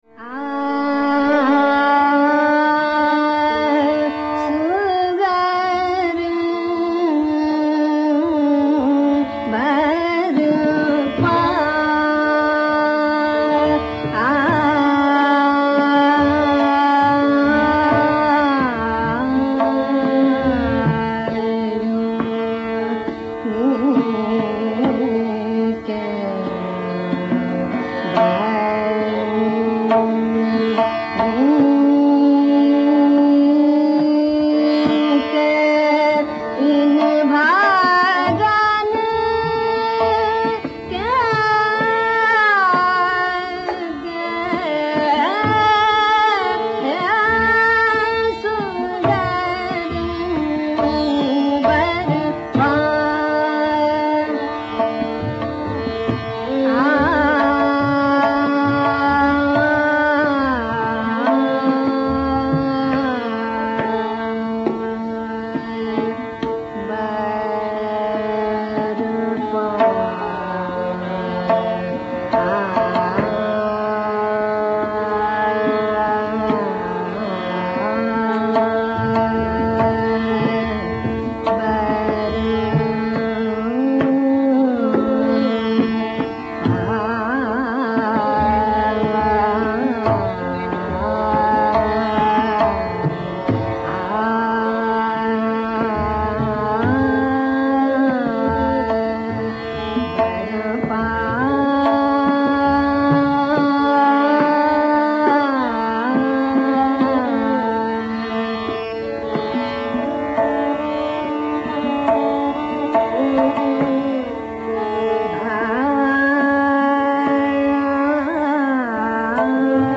The reader may have noticed the introduction of komal nishad (a key lakshana) right away on the first syllable of the mukhdas of both the compositions.